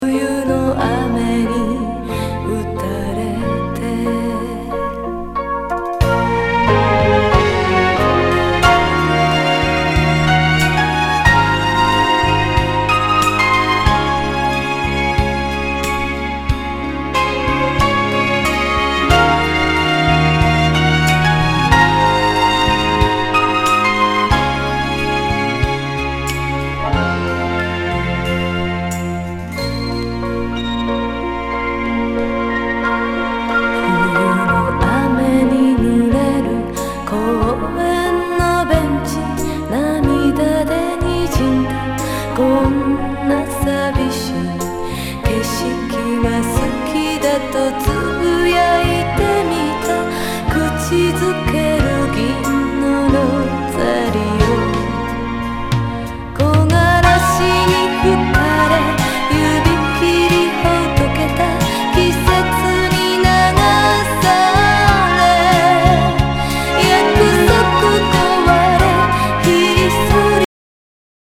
メロウな